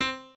pianoadrib1_39.ogg